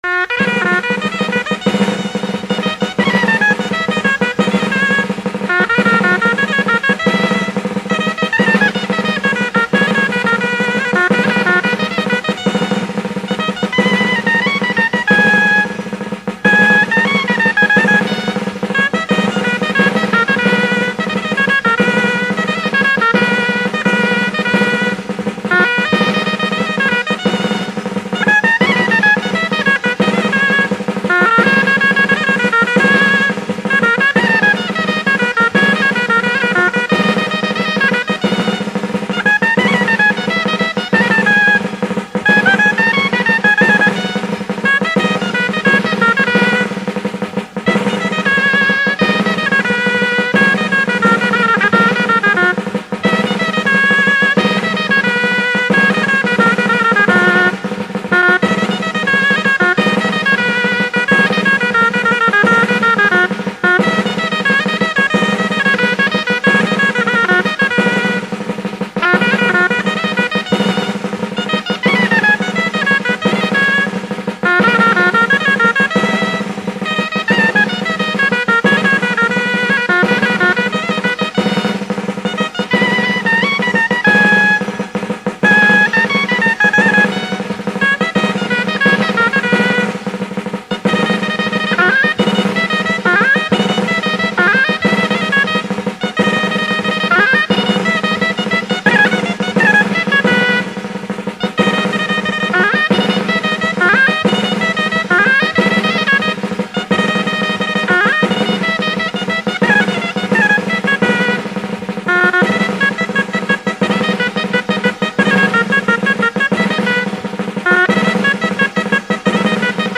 "Habas Verdes". Pieza solemne de hermosísima composición; y que siendo una, cada Dulzainero la hace distinta, siendo así reflejo de la libertad en unidad que nos caracteriza y símbolo del modo de ser castellano. Debe de ser tocada antes del Concejo Abierto por el Dulzainero más antiguo, y ser escuchada respetuosamente.